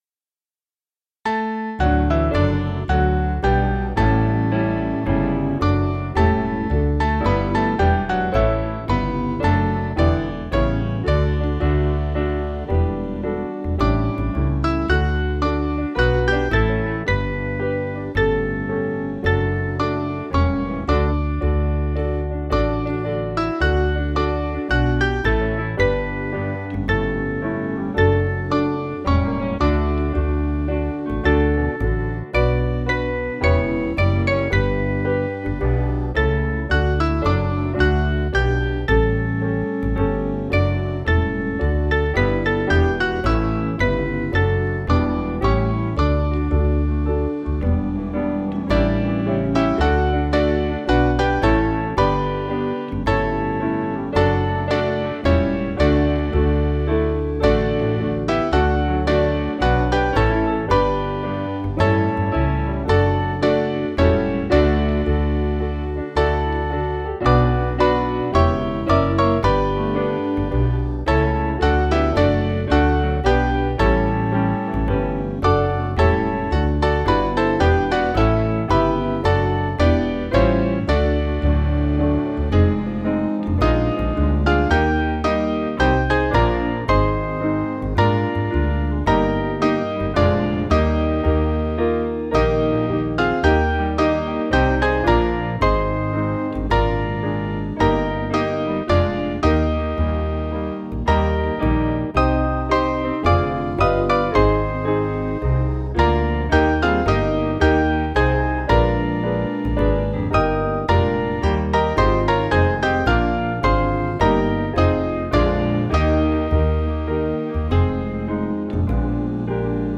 Mainly Piano
3/D-Eb 480.2kb